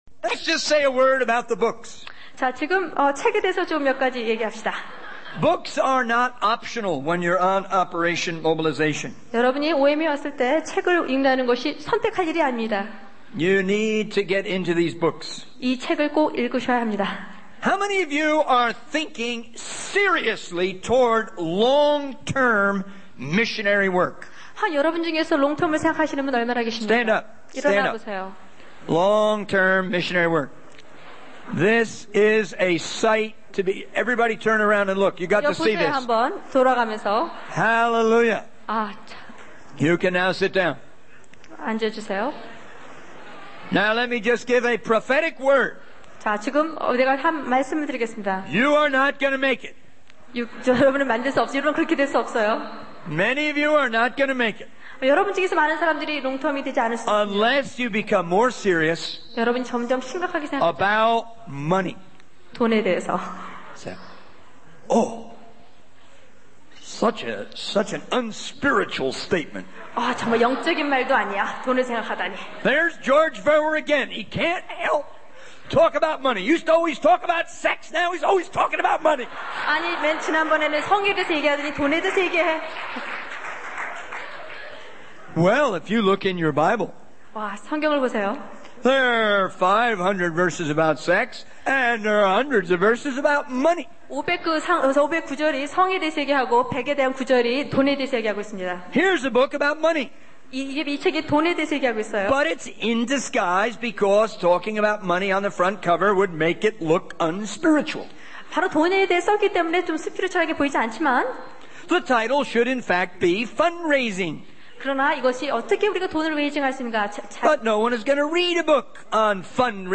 In this sermon, the speaker emphasizes the importance of taking ownership of the vision to carry the gospel to every village, every people, and every person in the world.